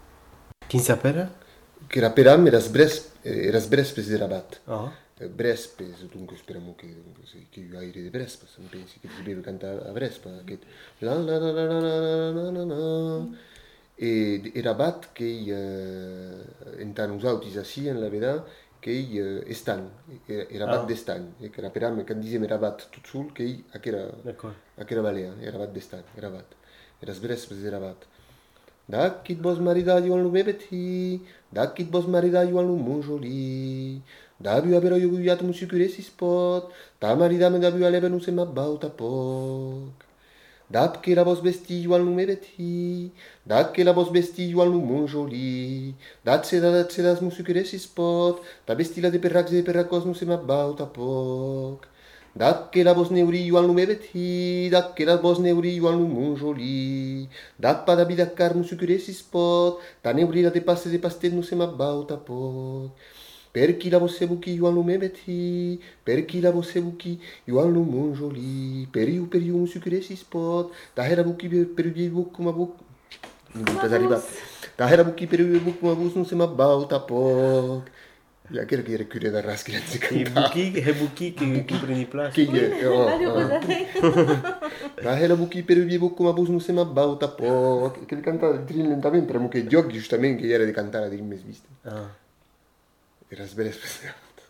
Aire culturelle : Bigorre
Genre : conte-légende-récit
Effectif : 1
Type de voix : voix d'homme
Production du son : chanté
Classification : parodie du sacré